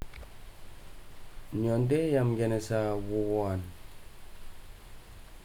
Elicitation of resultative structures in Daakaka.
digital wav file recorded at 44.1 kHz/16 bit on Marantz PMD 620 recorder; ELAN eaf file
Port Vila, Efate, Vanuatu